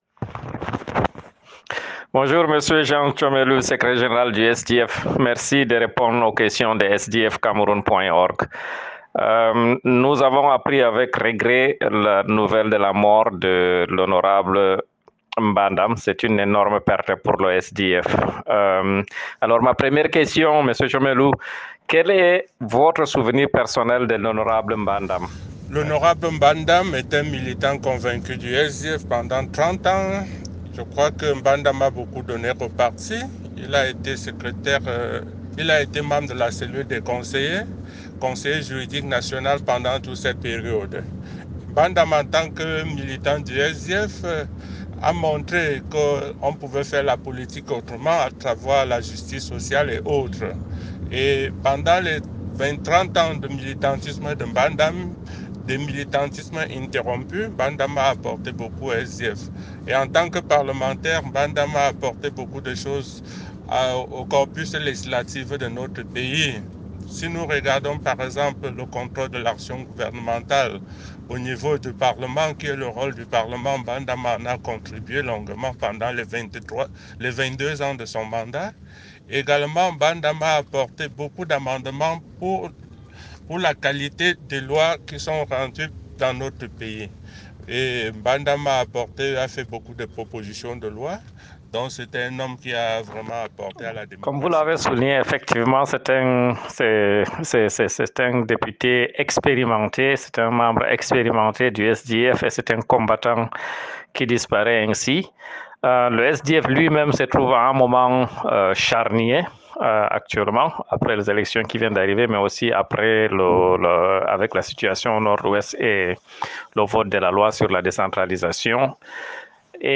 VERSION AUDIO DE L'INTERVIEW: